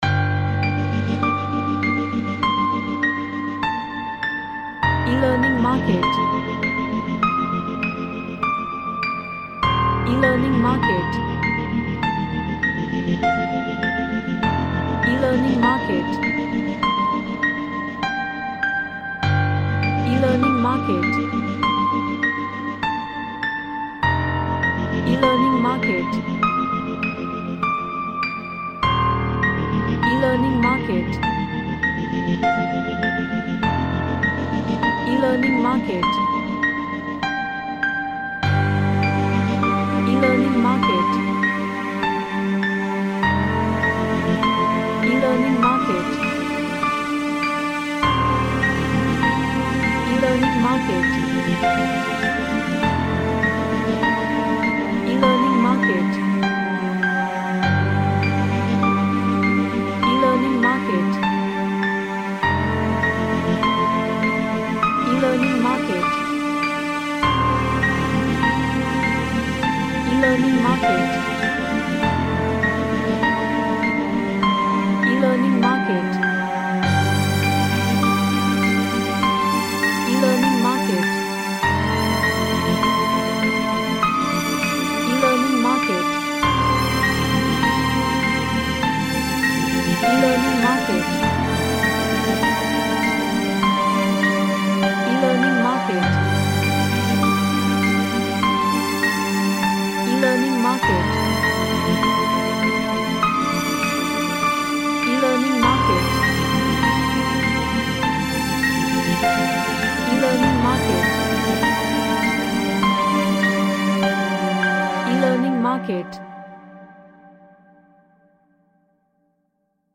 A simple piano & orchestral element track
Epic / Orchestral